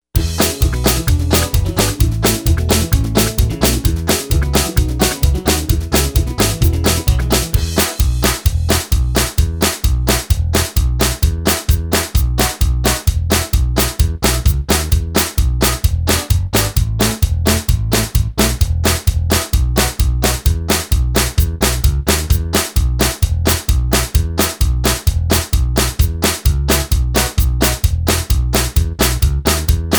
two instrumentals